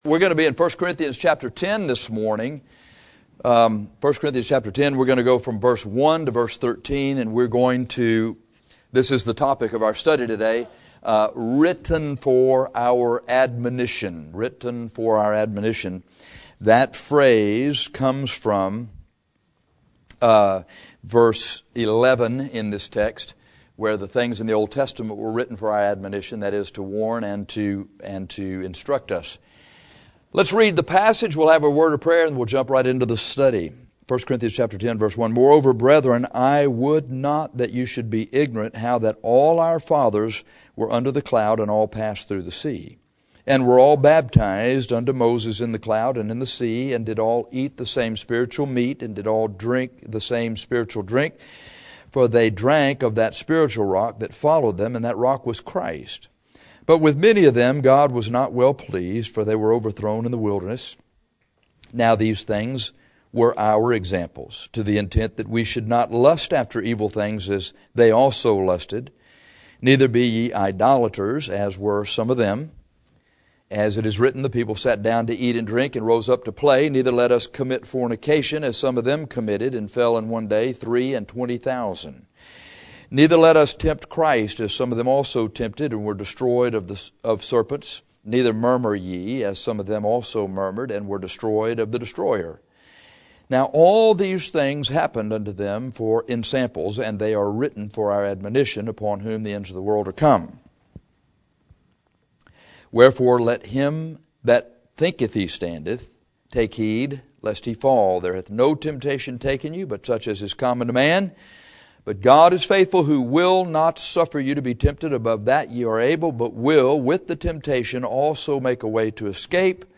Written for Our Admonition 1 Cor 10:1-13 - Bible Believers Baptist Church